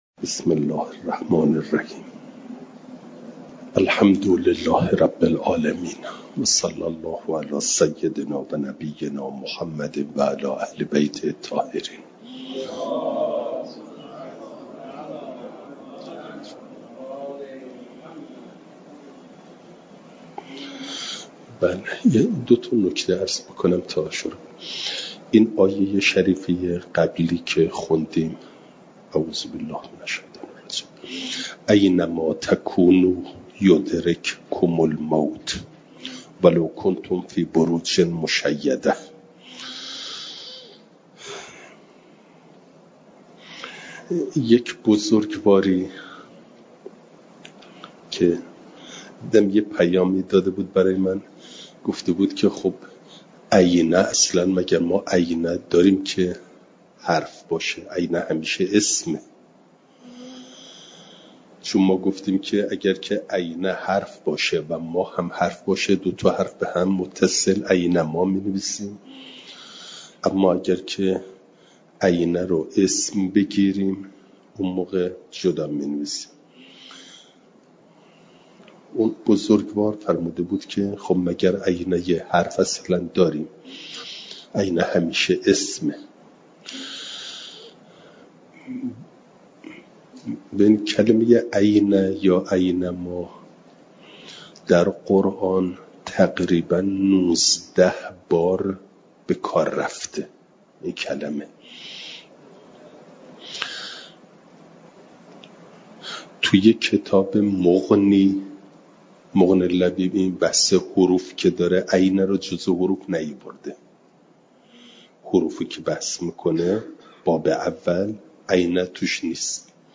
جلسه سیصد و هفتاد و پنجم درس تفسیر مجمع البیان